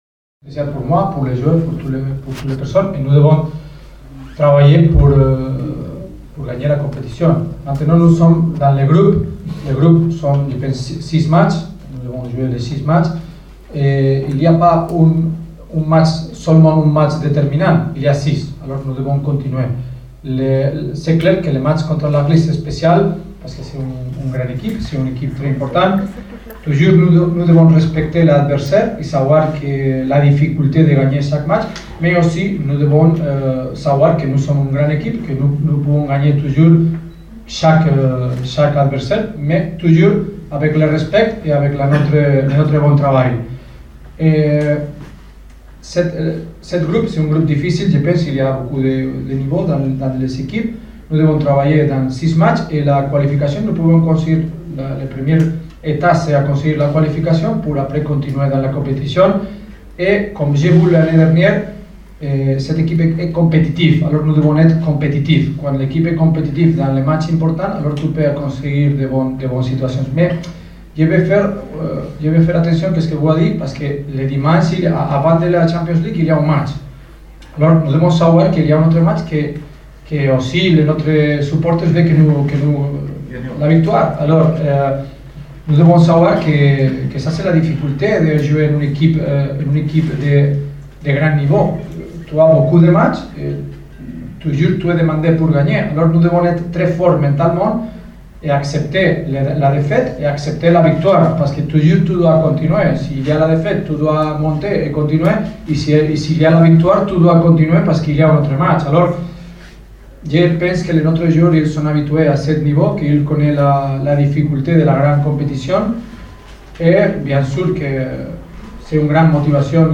تصريح المدرب الجديد للنجم الساحلي :